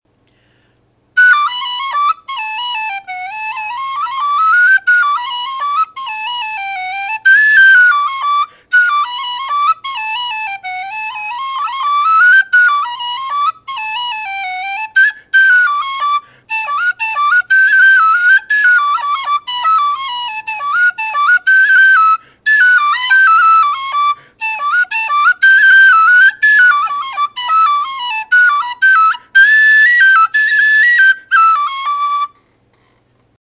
Sound clips of the whistle:
Tone: Strong bottom end, with a little bit of windiness. The hissing windiness increases as you go up into the second octave.
Volume: Louder than average.